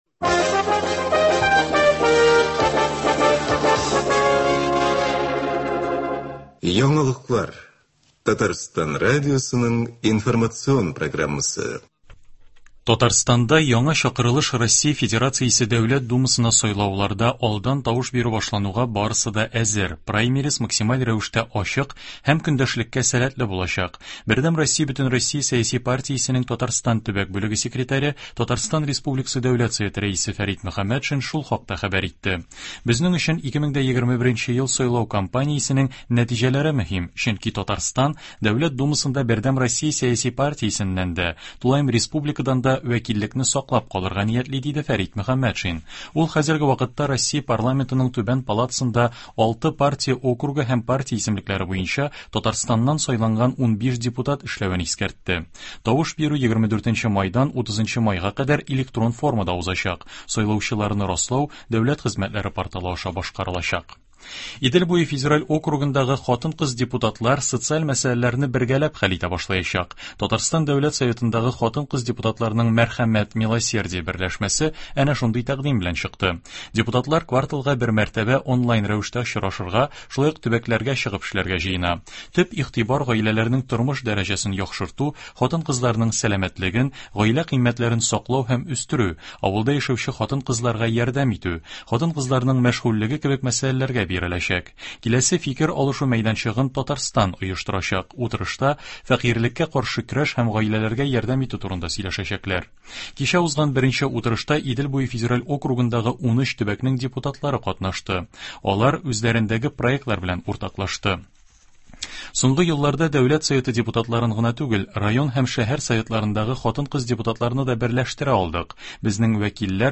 Яңалыклар (02.03.21)